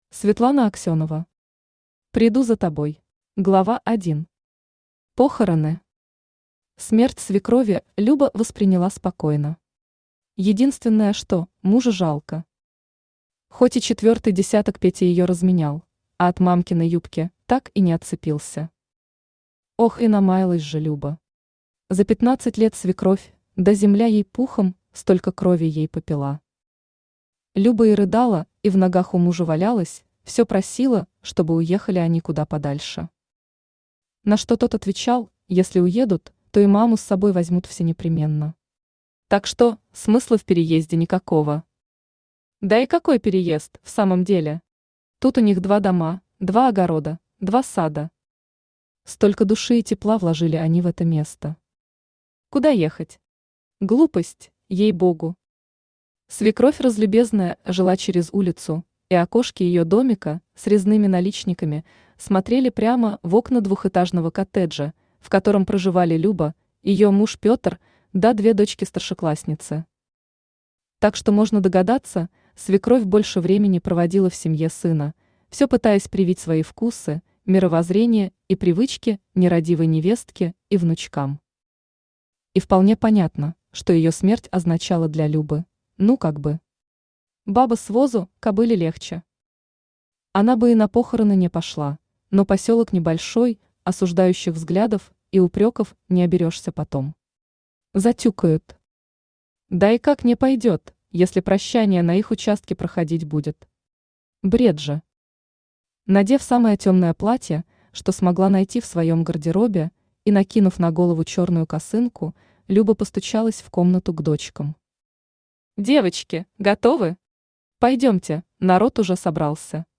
Автор Светлана Алексеевна Аксенова Читает аудиокнигу Авточтец ЛитРес.